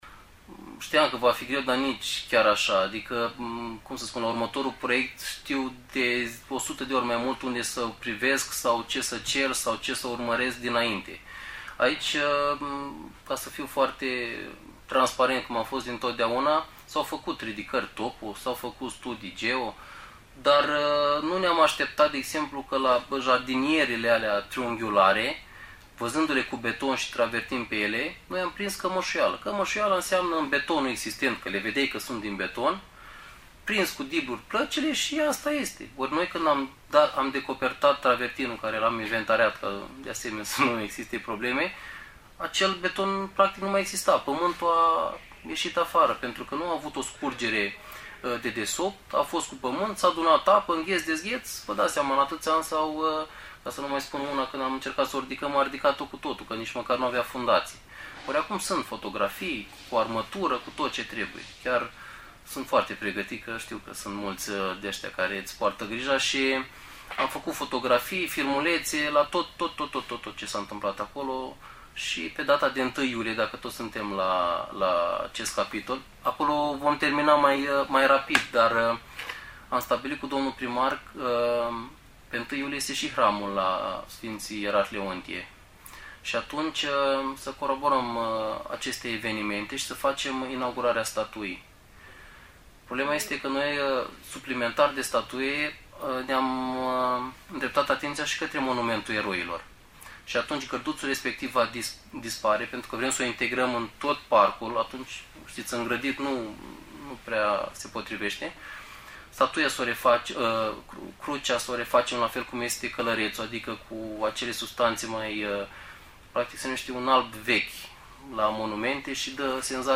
Loghin a spus, la VIVA FM, cum decurg lucrările și data la care va fi inaugurată statuia, dar și Monumentul Eroilor.